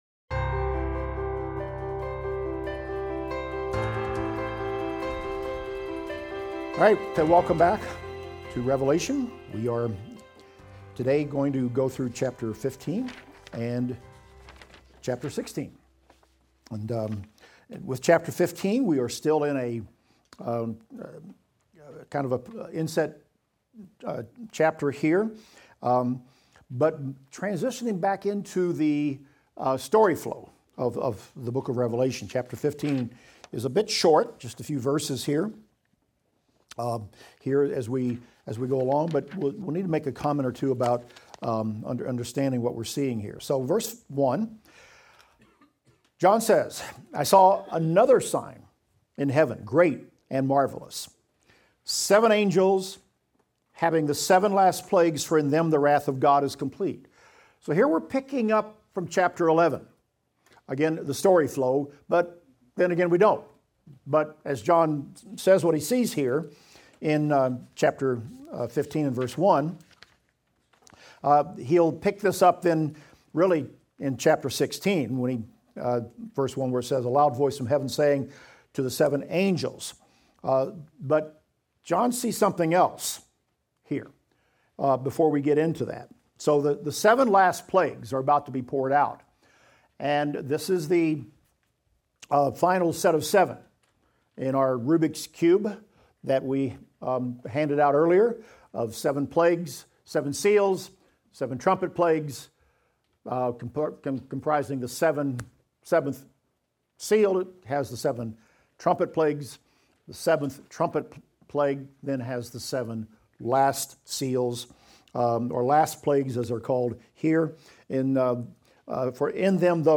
Revelation - Lecture 46 - audio.mp3